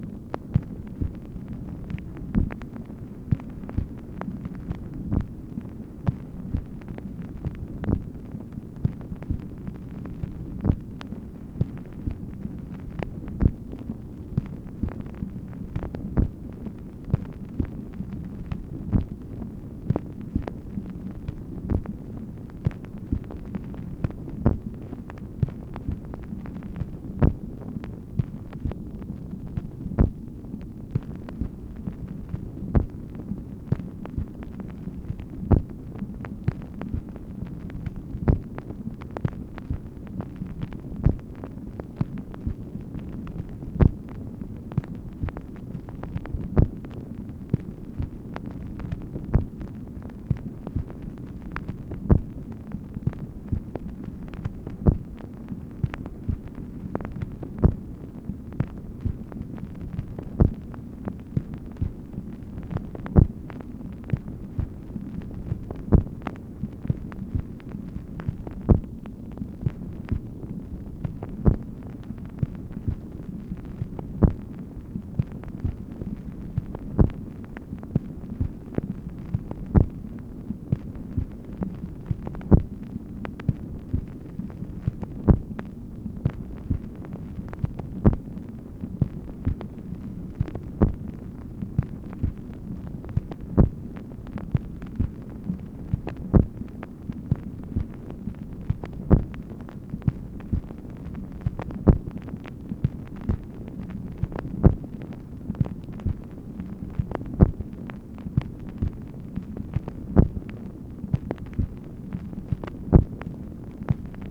MACHINE NOISE, January 25, 1964
Secret White House Tapes | Lyndon B. Johnson Presidency